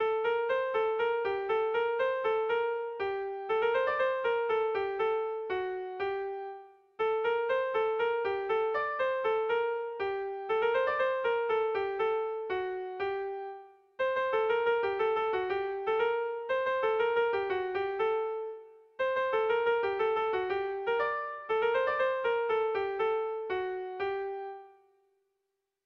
Bertso melodies - View details   To know more about this section
Erromantzea